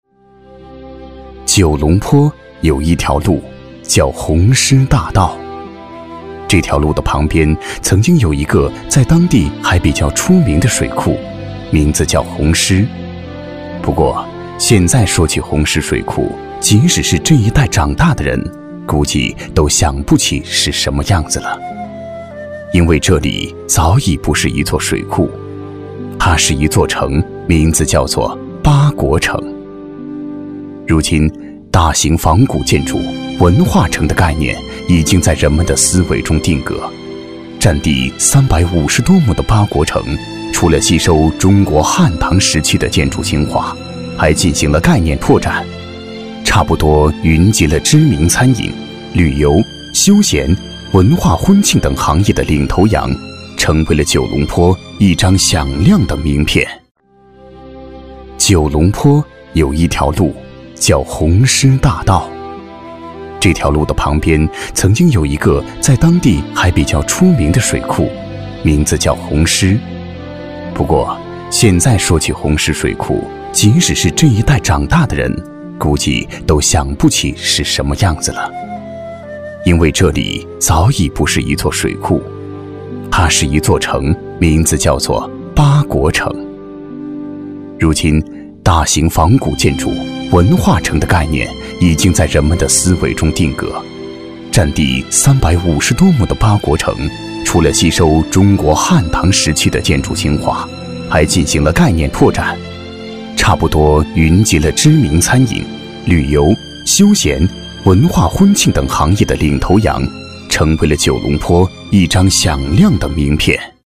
• 男S337 国语 男声 专题片-巴国城-厚重、感情 大气浑厚磁性|沉稳